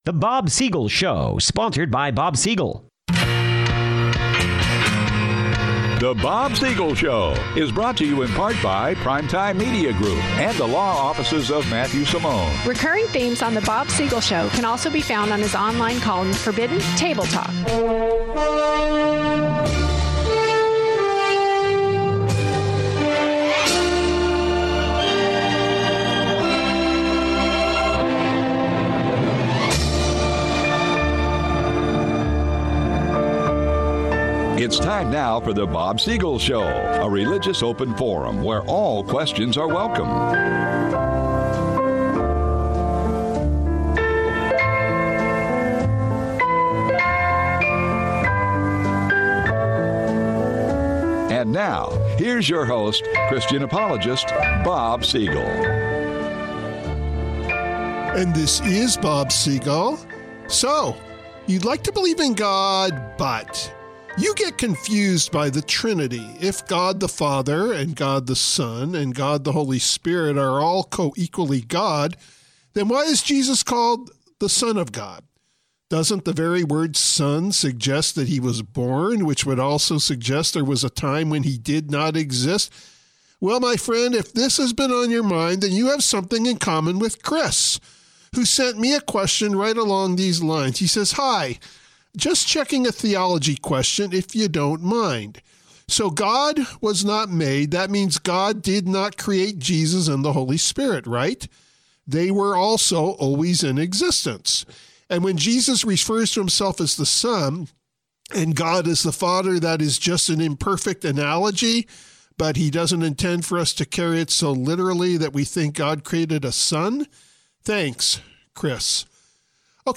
serious, passionate commentary